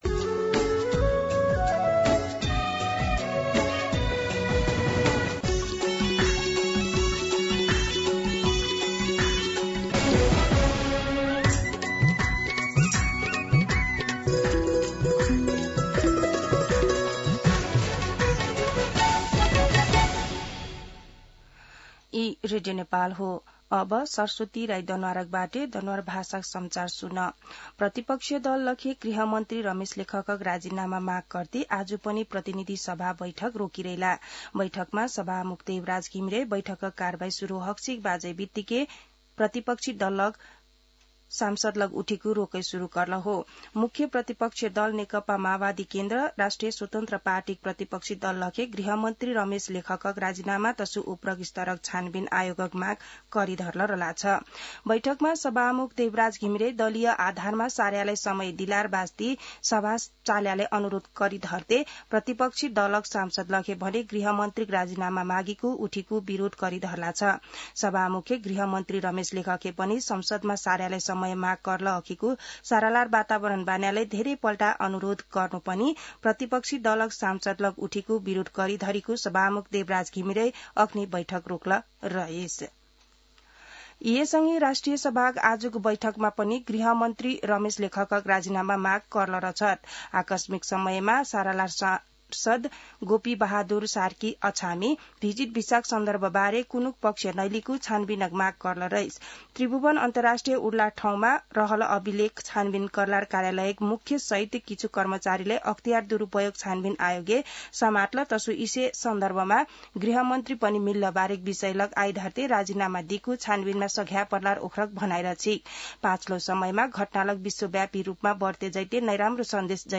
दनुवार भाषामा समाचार : १४ जेठ , २०८२